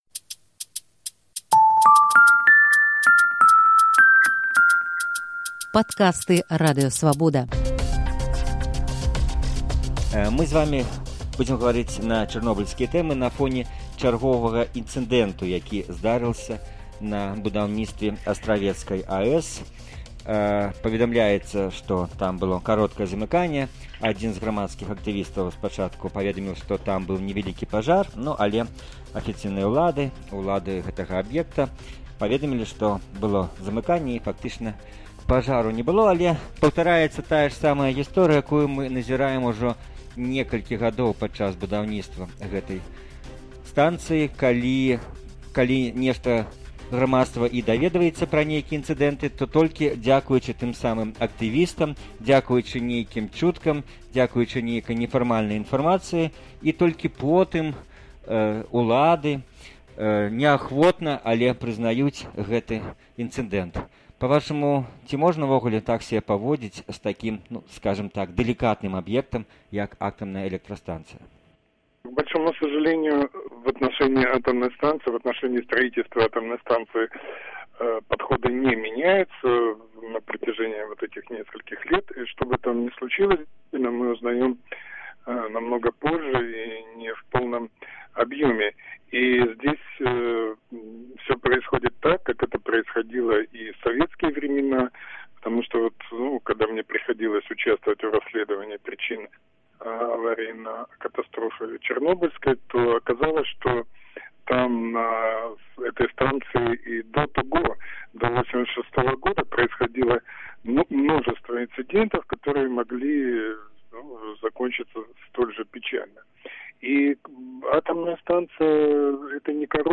Госьць “Інтэрвію тыдня” — фізык, кандыдат тэхнічных навук, былы сябра Чарнобыльскай камісіі Вярхоўнага Савету СССР Юры Варонежцаў.